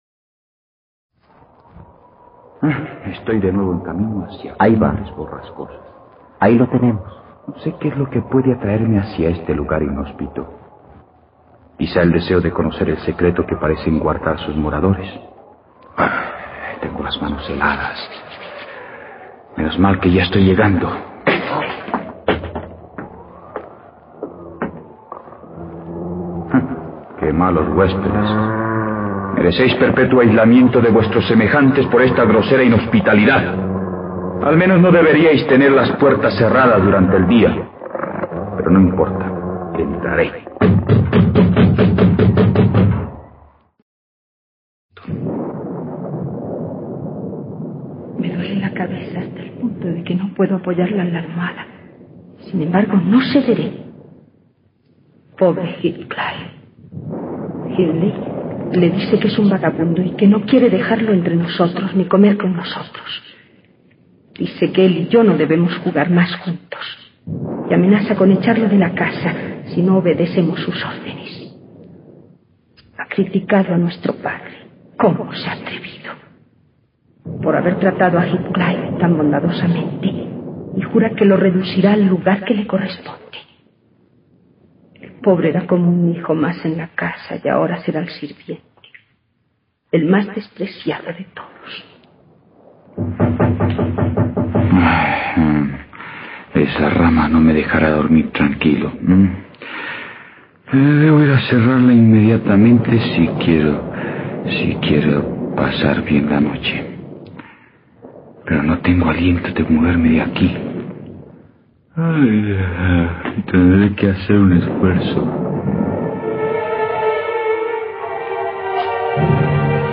Cumbres borrascosas en la radio colombiana (1957)
Radioteatro dominical, Cumbres borrascosas. Radio Nacional.